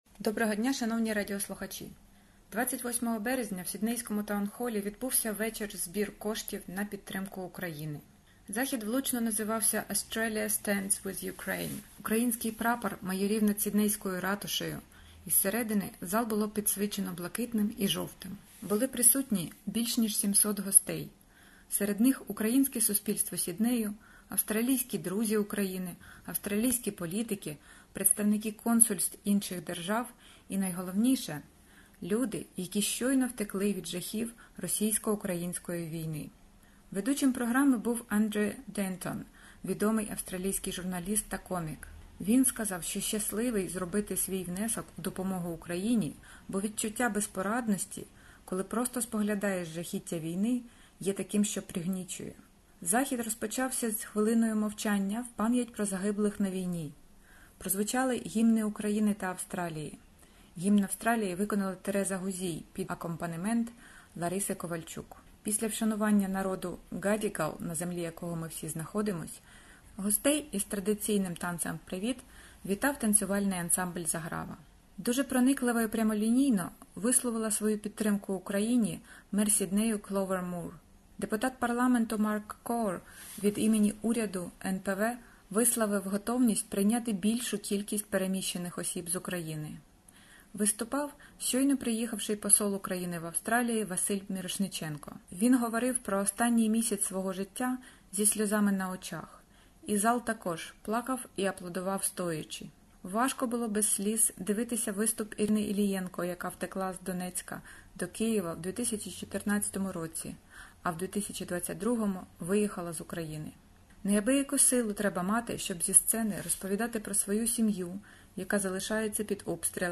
28 березня в Сіднейському Тown Hall відбувся вечір-збір коштів на підтримку України - Australia stands with Ukraine. Були присутніми понад 700 гостей: серед них - представники українського суспільства Сіднею, австралійські друзі України, австралійські політики, представники консульств інших держав і найголовніше - люди, які щойно втекли від жахів російсько-української війни.